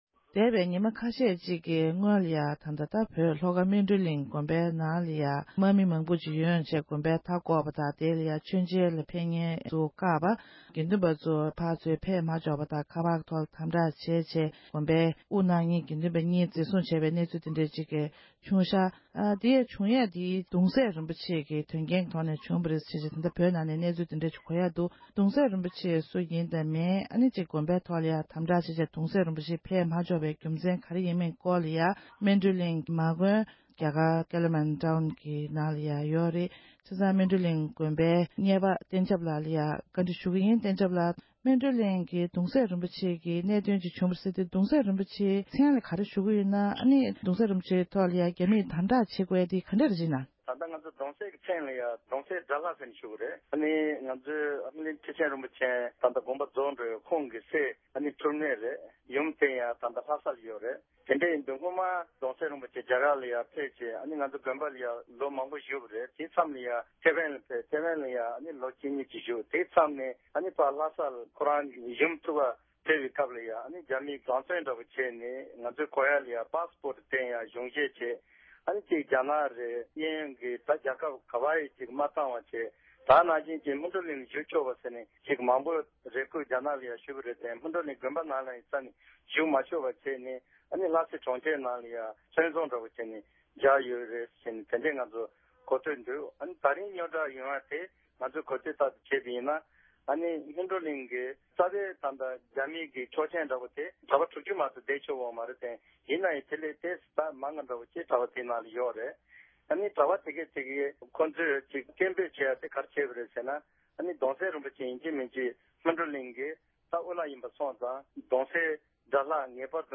སྒྲ་ལྡན་གསར་འགྱུར། སྒྲ་ཕབ་ལེན།
བཀའ་འདྲི་ཞུས་པར་གསན་རོགས༎